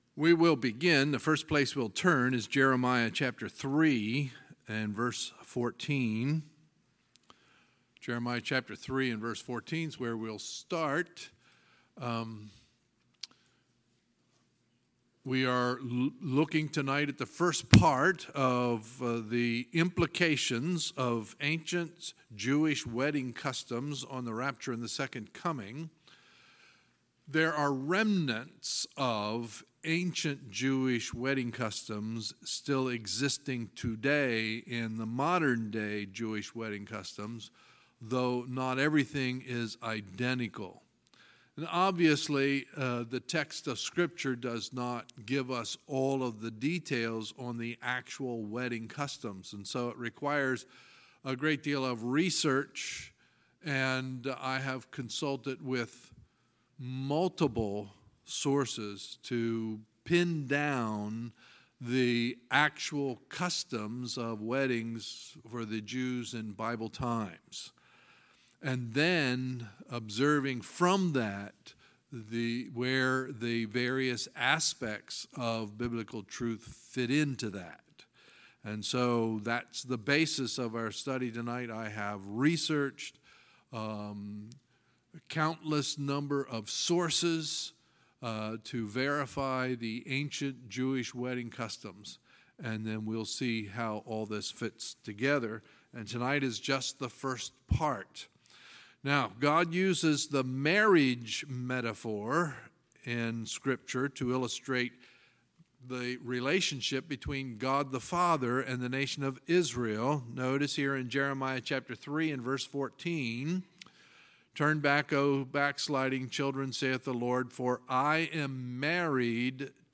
Sunday, June 17, 2018 – Sunday Evening Service
Sermons